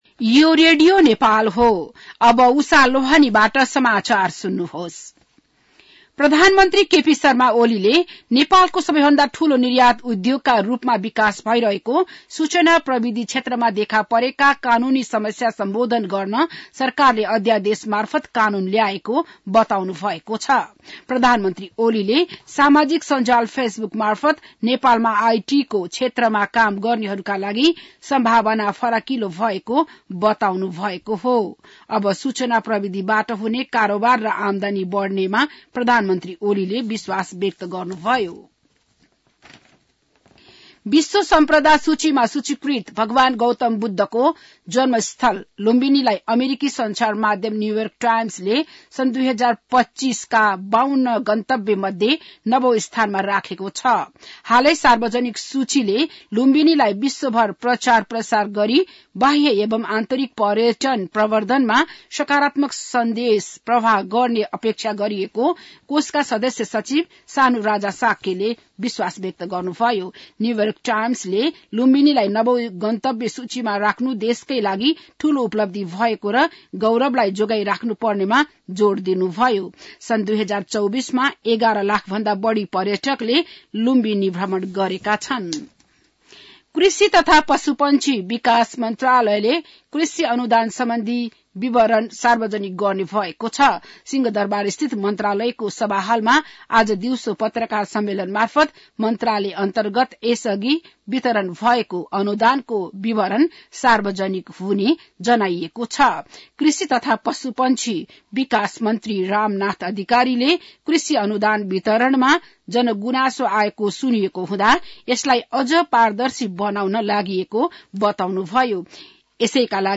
बिहान ११ बजेको नेपाली समाचार : २९ पुष , २०८१
11-am-news.mp3